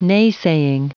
Prononciation du mot : nay-saying
nay-saying.wav